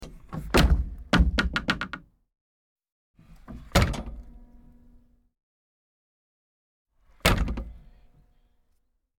Звуки экскаватора
Шум дверцы экскаватора при неплотном закрытии